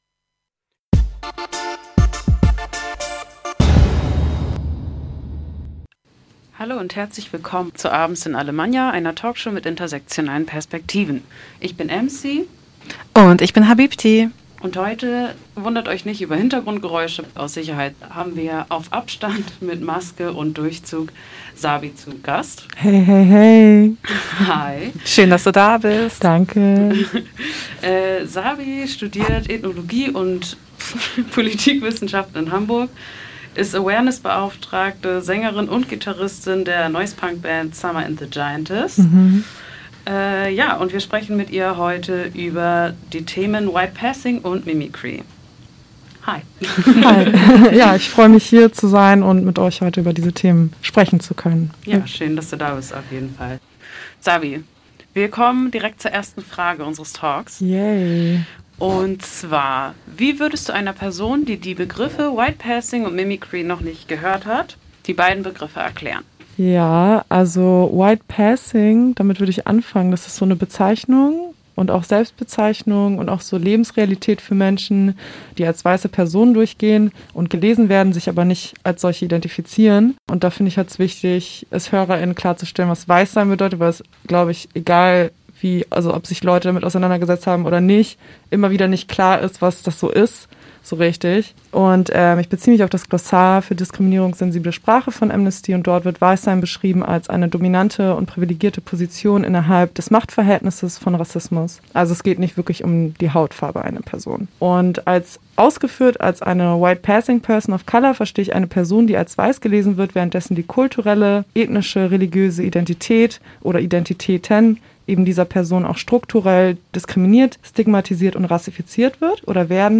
Eine Talkshow mit intersektionalen Perspektiven